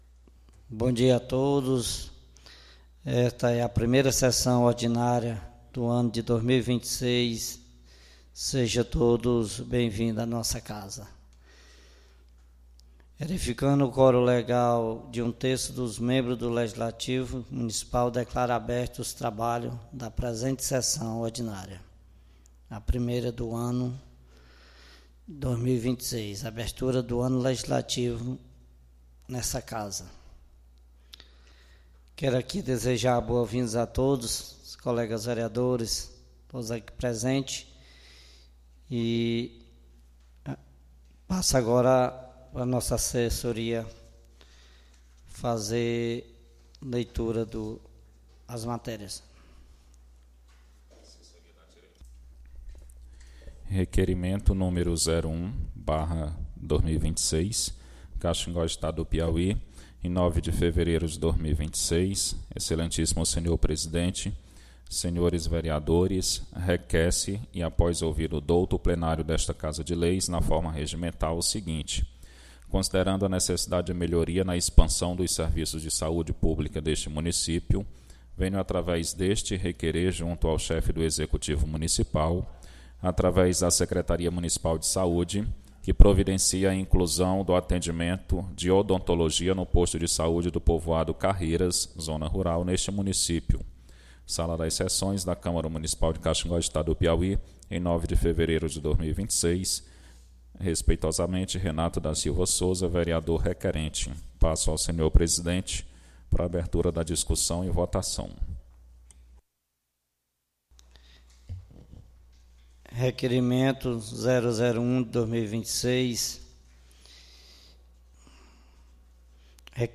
SAPL - Câmara Municipal de Caxingó - Piauí
Tipo de Sessão: Ordinária